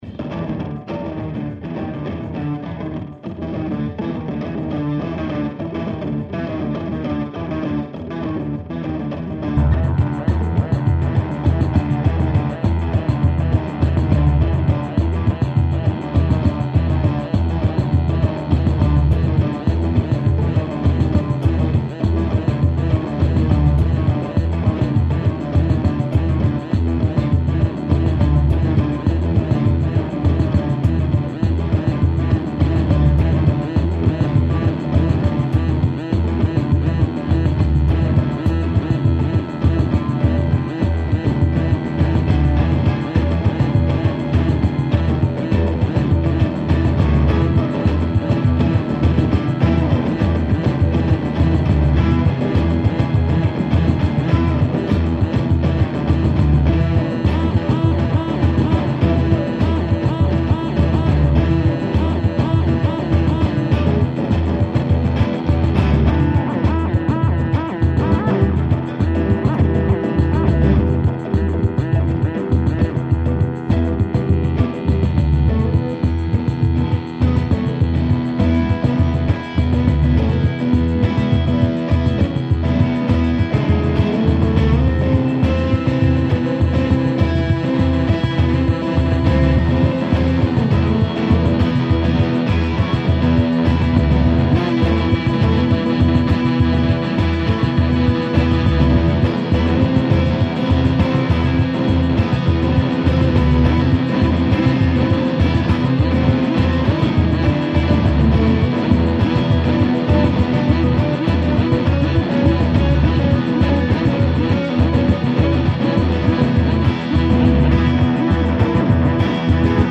und zwar live aufgenommen.
im salon hansen am 201208.
und eben abgemischt.